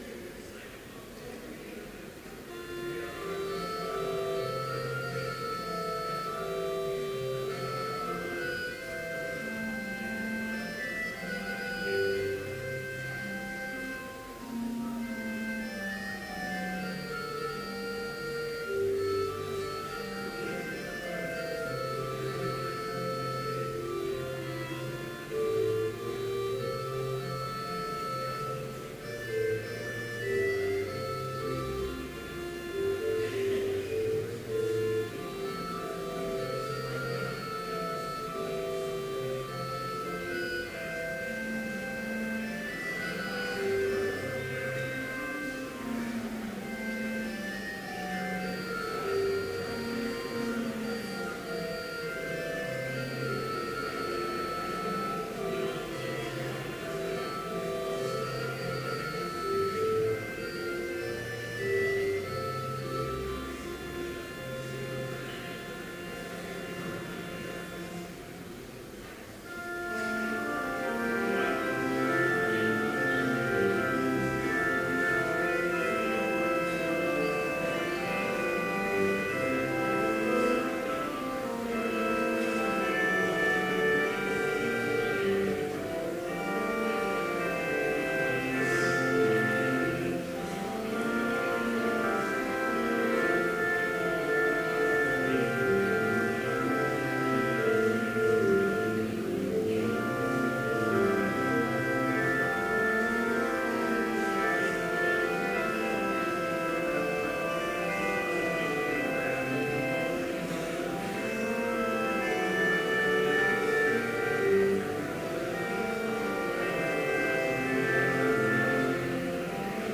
Complete service audio for Chapel - May 12, 2014